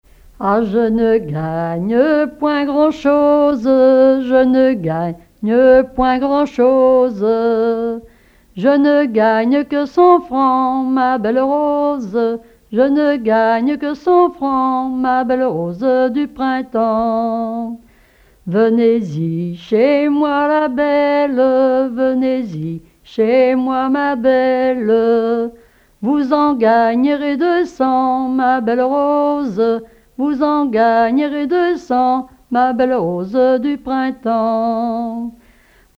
Genre laisse
collecte en Vendée
Témoignages et chansons traditionnelles
Pièce musicale inédite